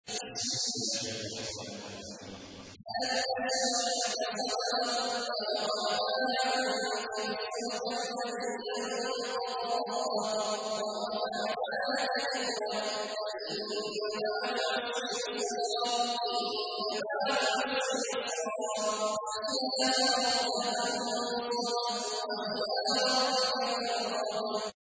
تحميل : 94. سورة الشرح / القارئ عبد الله عواد الجهني / القرآن الكريم / موقع يا حسين